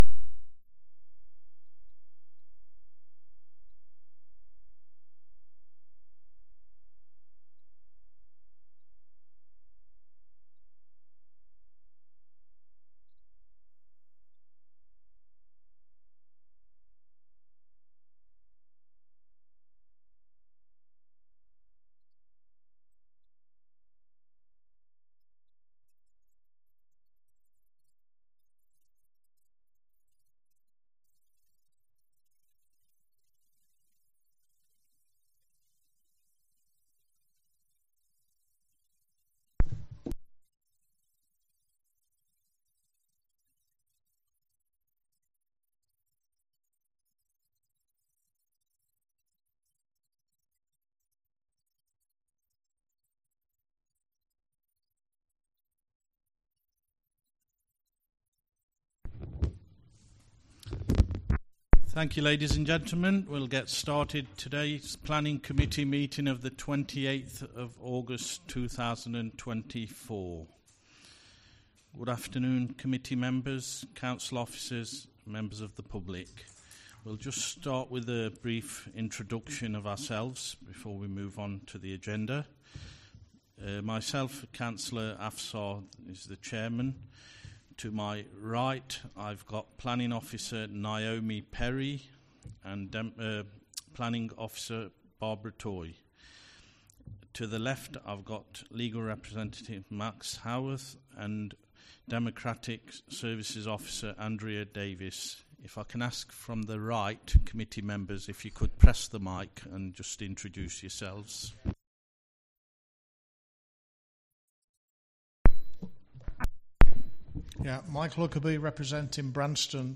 Committee Planning Committee Meeting Date 28-08-24 Start Time 2.00pm End Time 3pm Meeting Venue Coltman VC Room, Town Hall, Burton upon Trent Please be aware that not all Council meetings are live streamed.
Meeting Recording 240828 compressed.mp3 ( MP3 , 16.94MB )